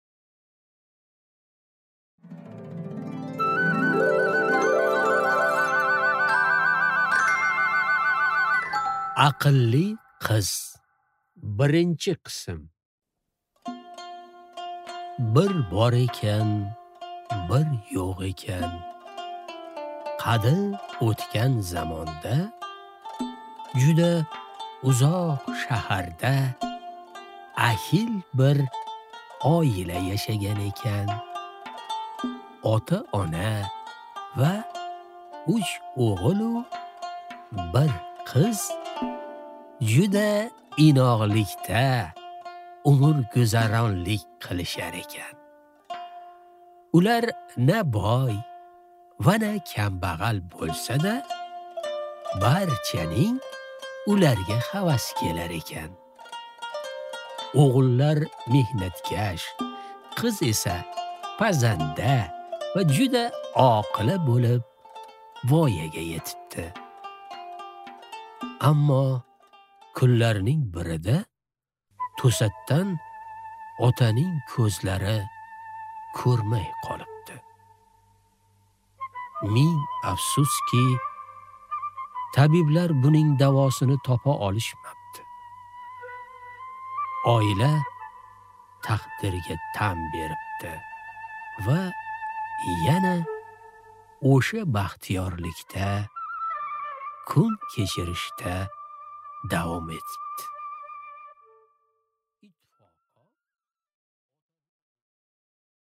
Аудиокнига Aqlli qiz | Библиотека аудиокниг